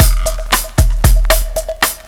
Bossa Back 08.WAV